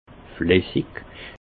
Ville Prononciation 68 Munster